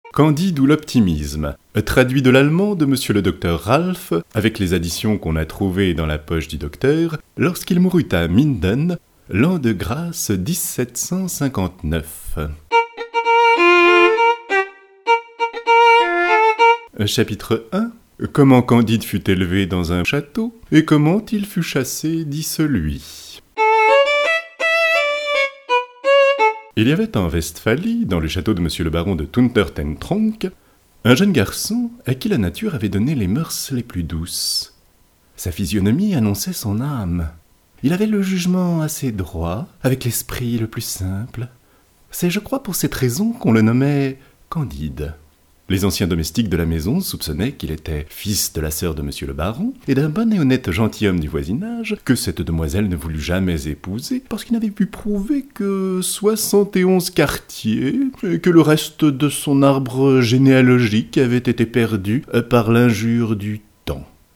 0% Extrait gratuit Candide ou l'Optimisme de François Marie Arouet Dit Voltaire Éditeur : Audiolude Paru le : 2010 Le célèbre conte philosophique de Voltaire en version audio.